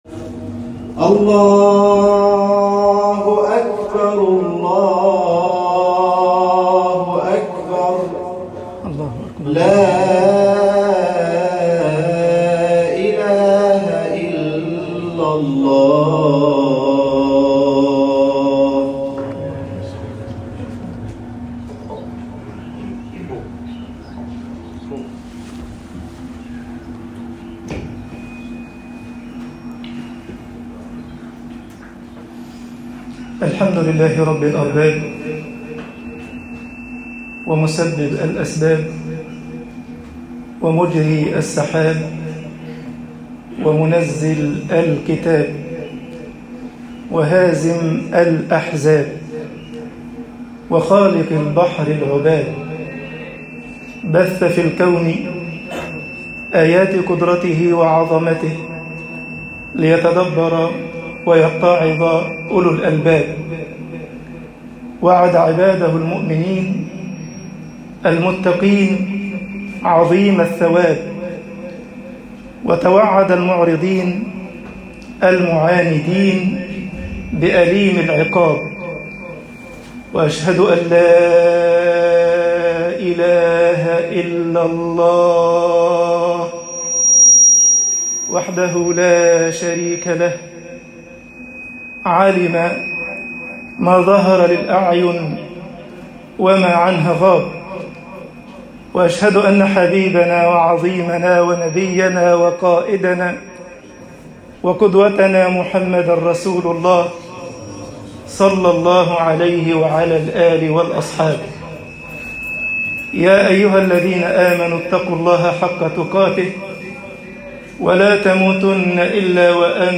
خطب الجمعة - مصر اسْتَعِينُوا باللَّهِ وَاصْبِرُوا